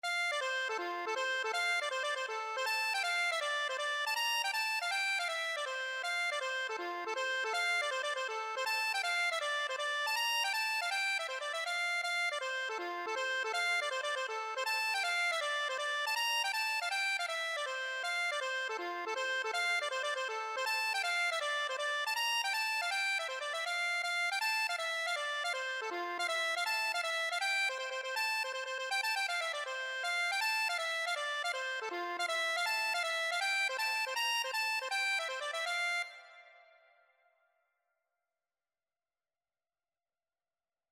Accordion version
F major (Sounding Pitch) (View more F major Music for Accordion )
2/4 (View more 2/4 Music)
Accordion  (View more Intermediate Accordion Music)
Traditional (View more Traditional Accordion Music)